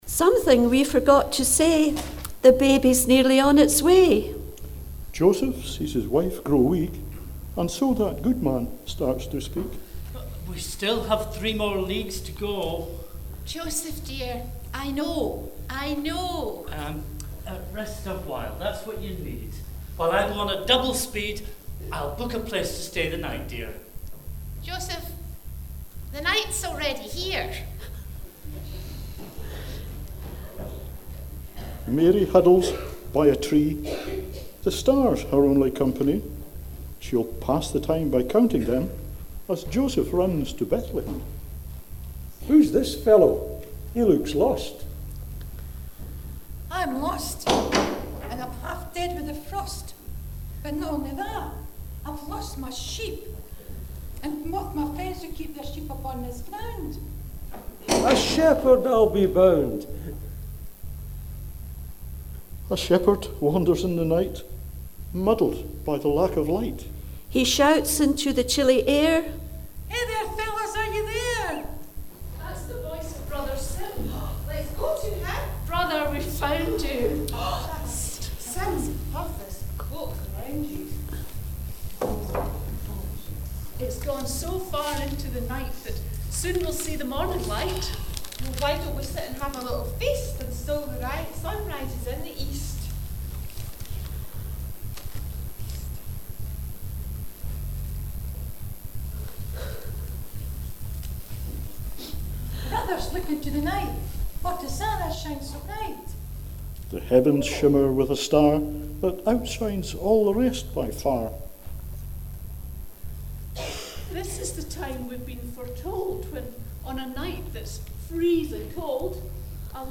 As the angels depart, again to 'Gloria' the shepherds set off for Bethlehem.